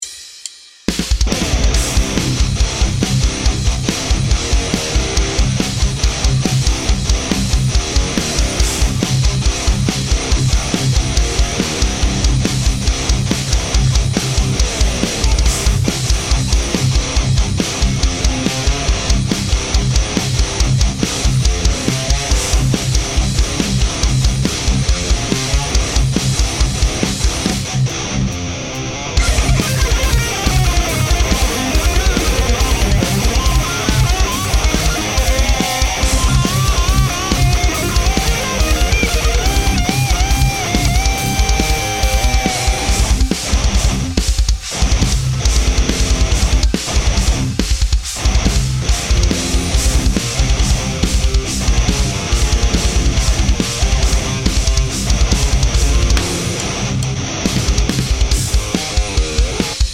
AD, Revalver, Le456, Imp, Sens HD515